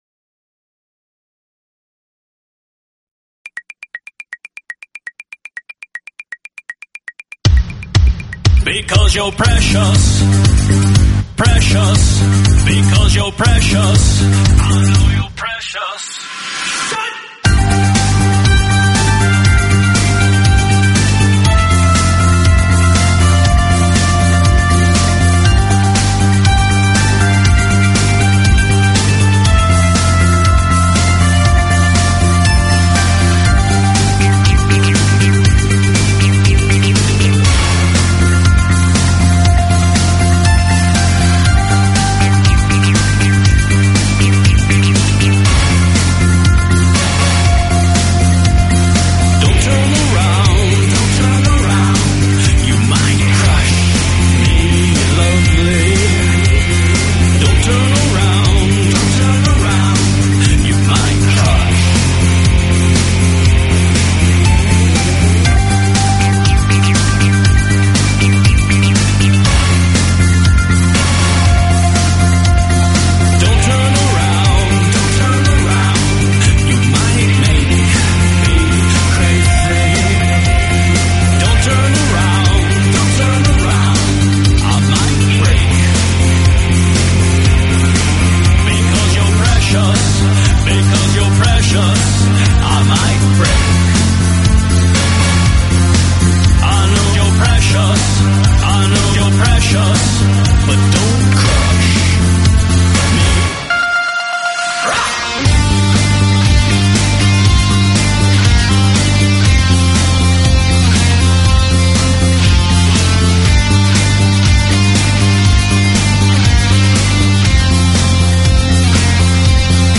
Talk Show Episode, Audio Podcast, Talking_with_the_Gs and Courtesy of BBS Radio on , show guests , about , categorized as